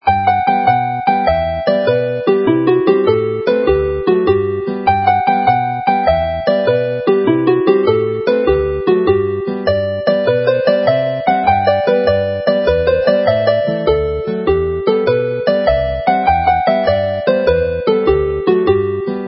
Llwytcoed Jig